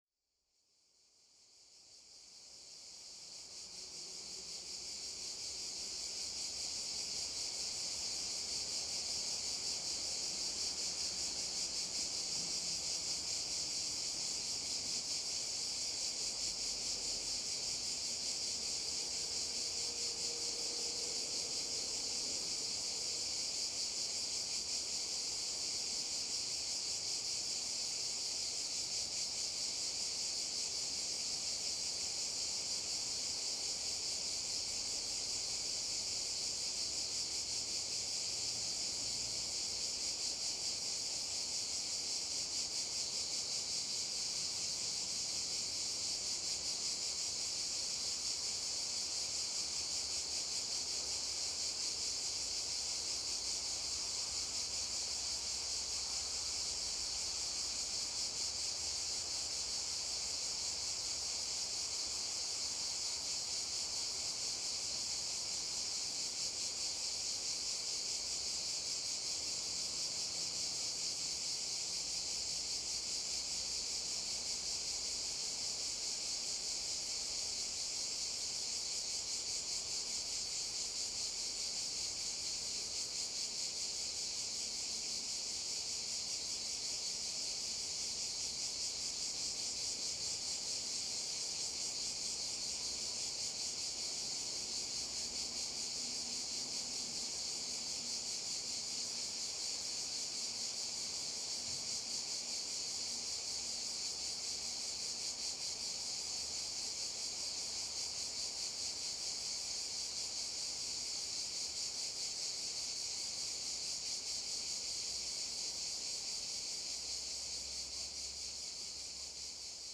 Cicadas
Ambisonic order : F (4 ch) 1st order 3D
Microphone name : DIY "soundfield" mic Array type : tetrahedron